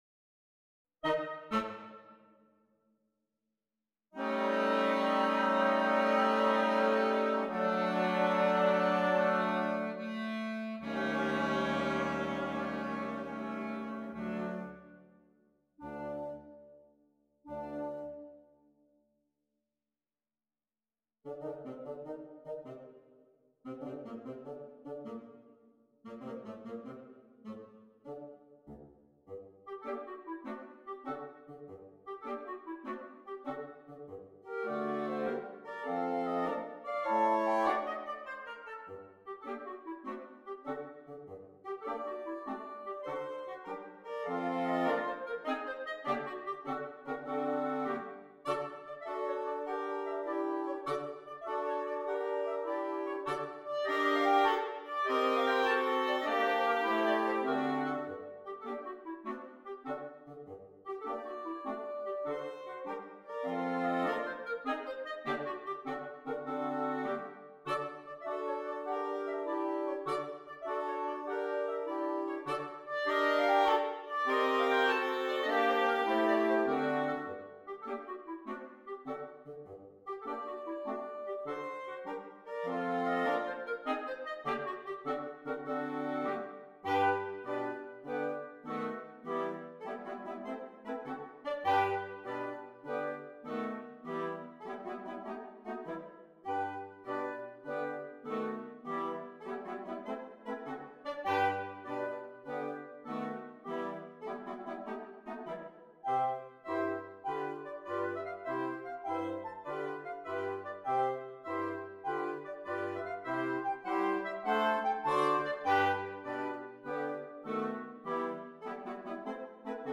Interchangeable Woodwind Ensemble
It is scored here for an interchangeable woodwind ensemble.
PART 1 - Flute, Oboe, Clarinet
PART 4 - Clarinet, Alto Saxophone, Tenor Saxophone, F Horn
PART 5 - Bass Clarinet, Bassoon, Baritone Saxophone